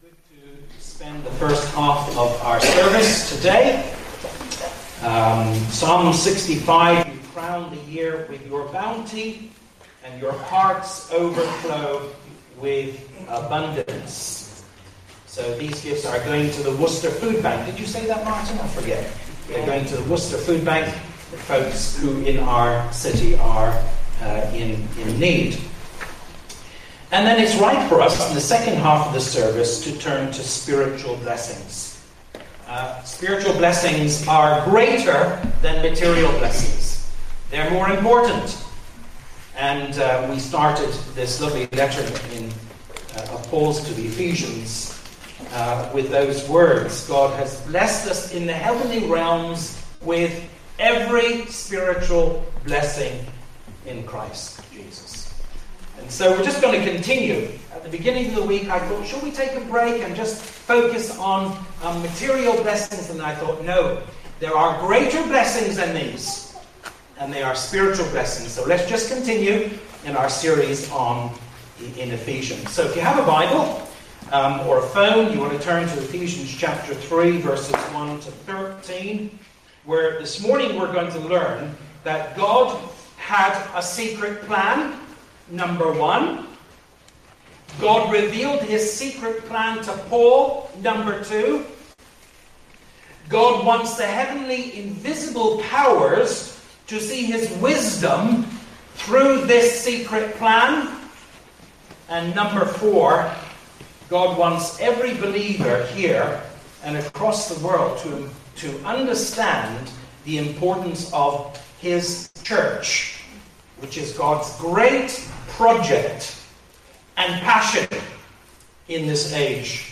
Sermon 31 Oct.mp3